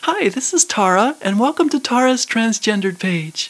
，在這之前，我先在這裡提供收集到的、經過訓練後的、三位小姐的聲音檔案